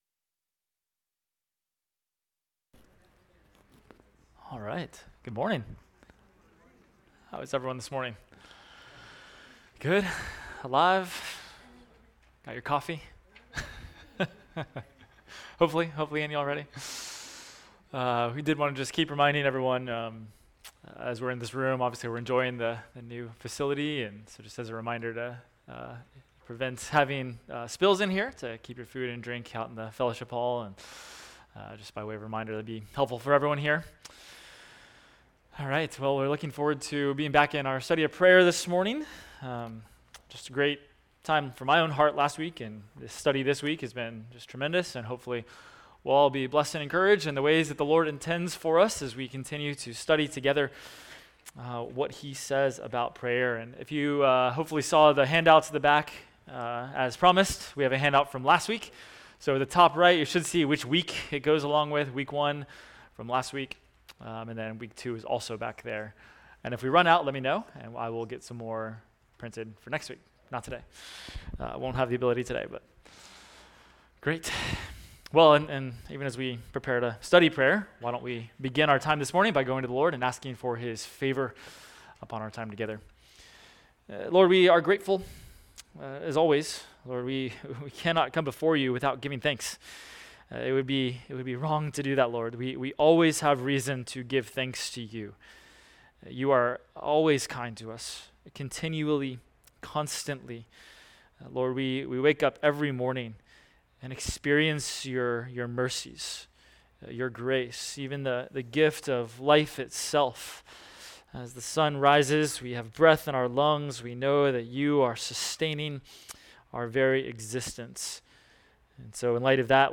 Adult Sunday School – Prayers of Scripture – Week 2